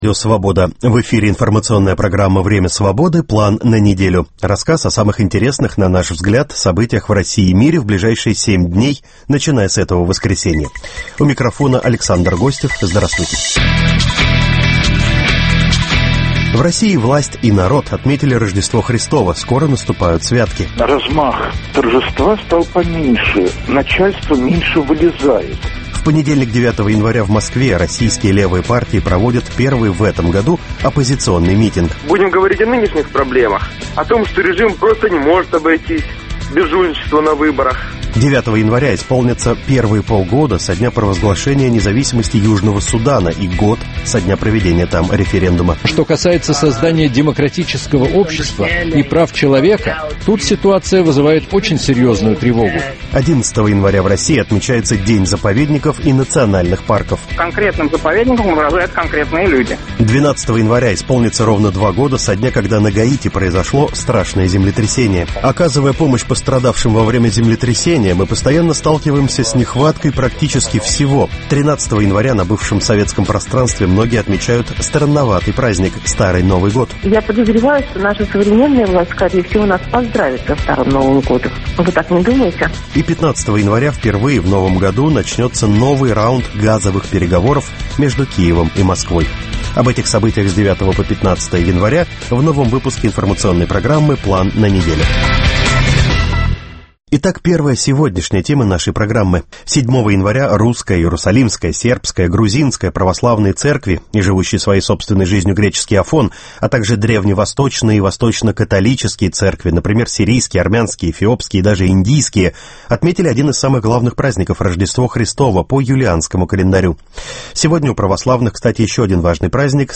Программу попеременно ведут редакторы информационных программ в Москве и Праге.